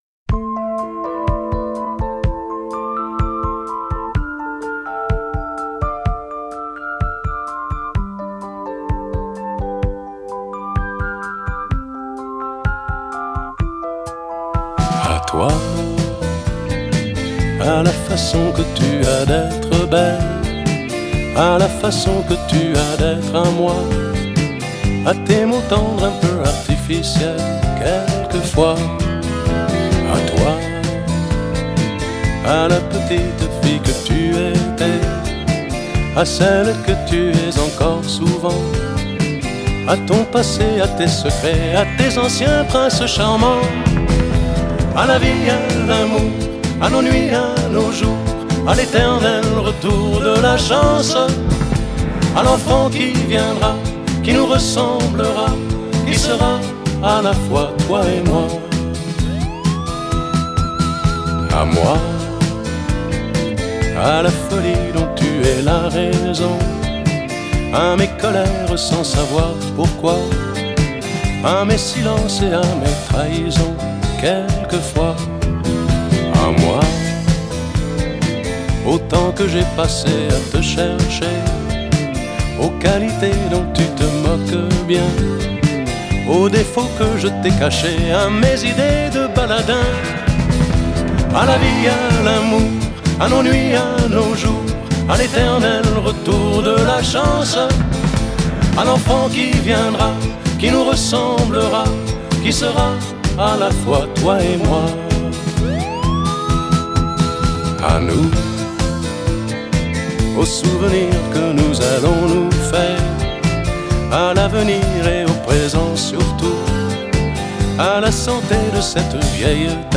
Жанр: Ретро, размер 6.49 Mb.